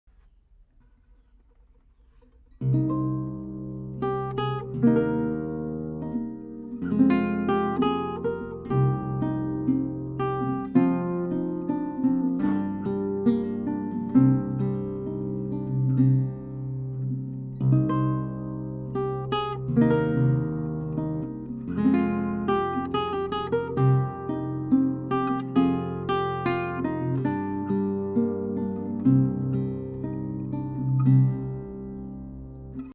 11-String Alto Guitar
This guitar has a AAA-grade solid Cedar top, solid East Indian Rosewood back & Sides, ebony fretboard, and creates a beautifully-balanced sound with nice trebles, deep basses, and amazing resonance and sympathetic sustain.
I have the guitar tuned in Romantic tuning in to G, a standard 11-string tuning where 1-6 are tuned up a minor third, and 7-11 descend step-wise: 7=D, 8=C, 9=B, 10 =A, 11=G. It can also be tuned in Dm tuning for Baroque Dm lute music.
Here are 12 quick, 1-take MP3s of this guitar, tracked using a pair of Schoeps CMT541 mics, into a pair of Ocean Audio 500 preamps using a Metric Halo ULN8 converter. This is straight, pure signal with no additional reverb, EQ or any other effects.